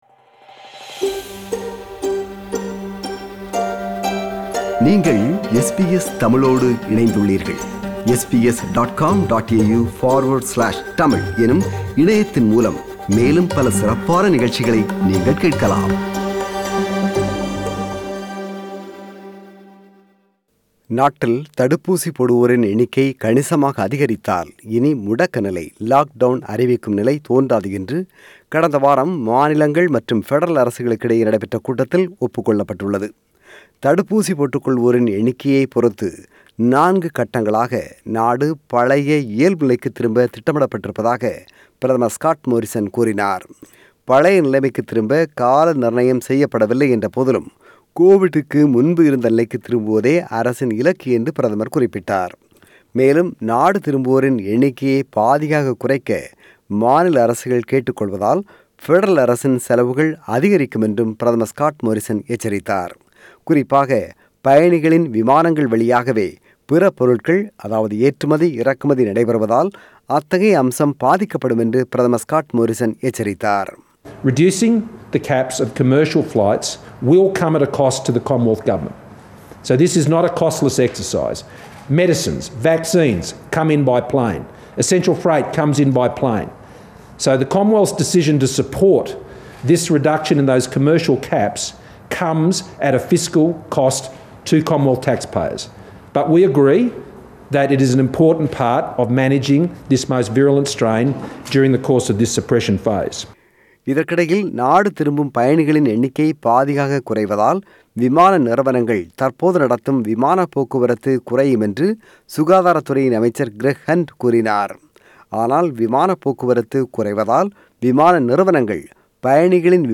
ஆஸ்திரேலியா திரும்பும் மக்களின் எண்ணிக்கையை பாதியாக குறைக்க மாநில மற்றும் பெடரல் அரசுகள் National Cabinet கூட்டத்தில் கடந்த வாரம் முடிவு செய்தன. ஆனால் இந்த முடிவு பலத்த விமர்சனத்தை ஏற்படுத்தியுள்ளது. இது குறித்த விவரணம்.